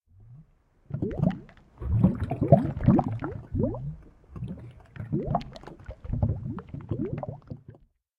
lava.ogg